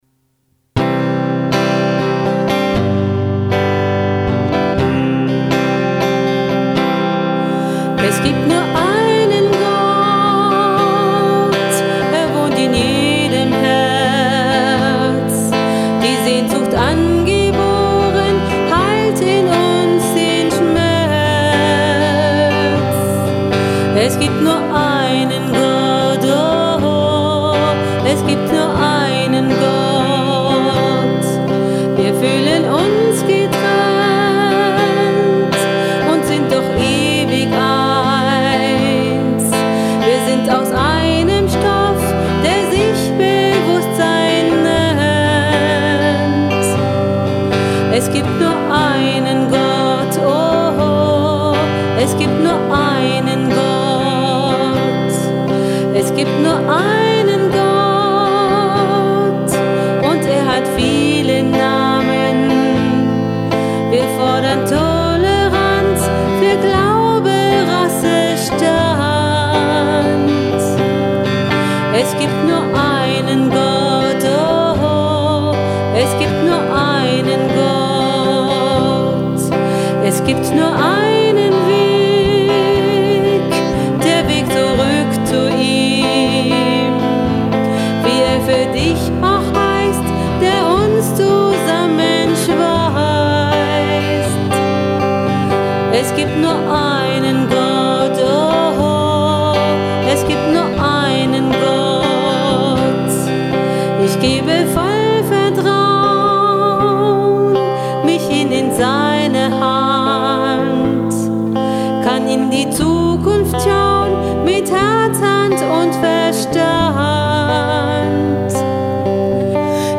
Themenwelt Kunst / Musik / Theater Musik Pop / Rock